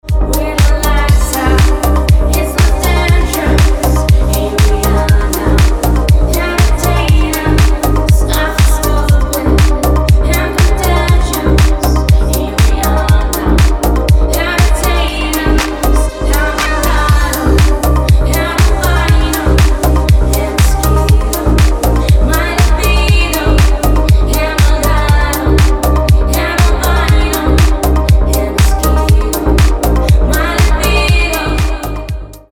• Качество: 320, Stereo
ритмичные
женский вокал
deep house
мелодичные
dance
спокойные
nu disco
красивый женский голос
звонкие
Disco House
в стиле Deep House, Disco House, Nu Disco